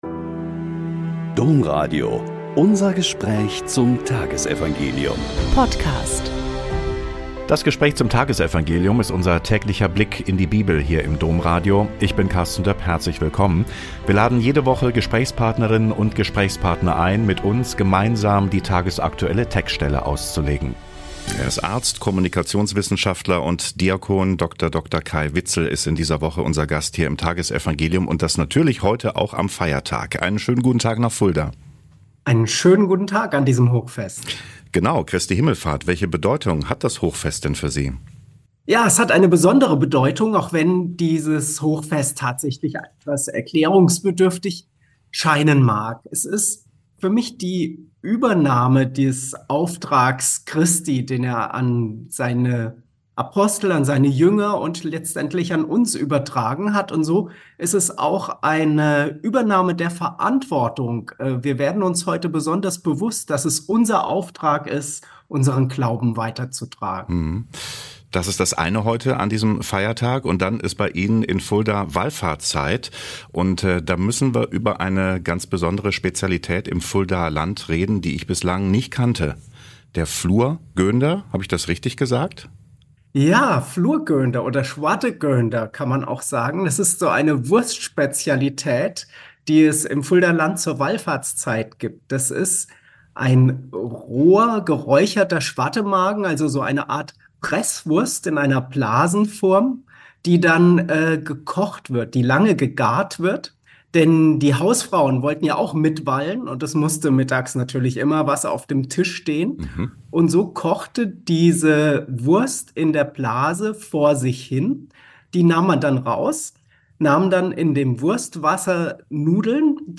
Mk 16,15-20 - Gespräch